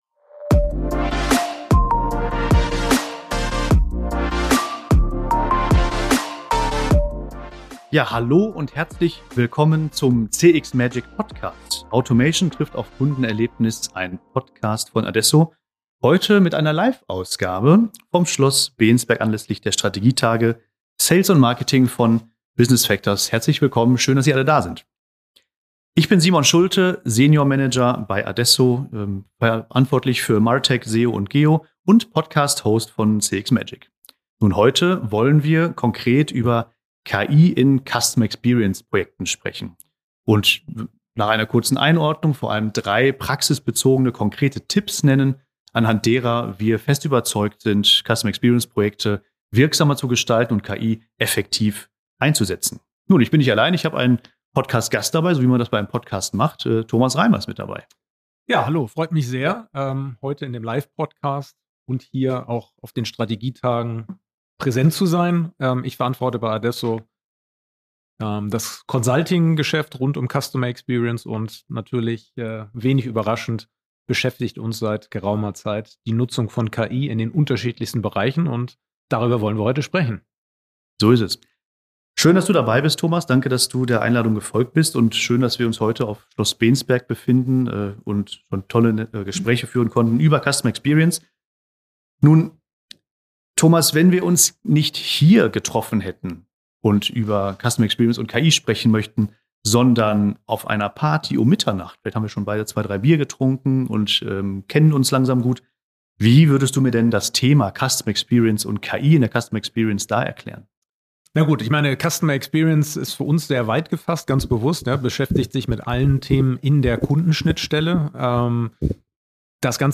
#20 Livepodcast